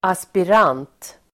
Uttal: [aspir'an:t]